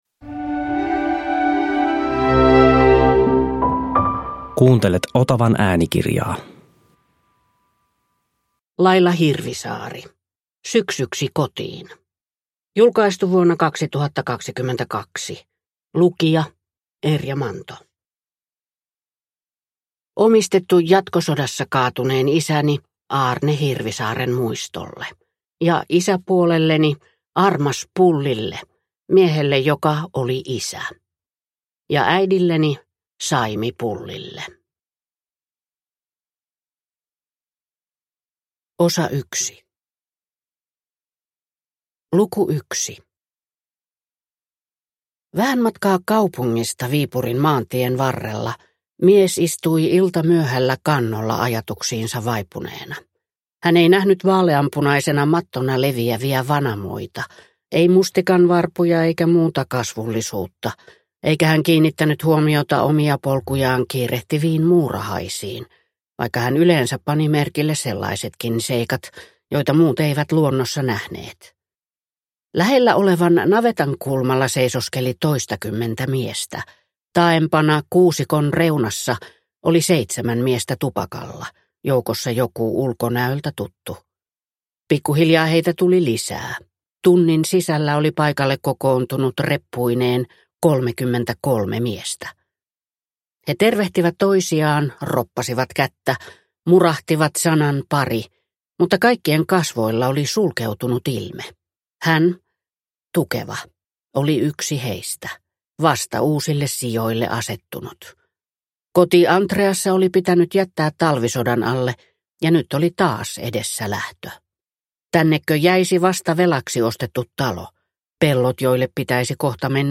Syksyksi kotiin – Ljudbok – Laddas ner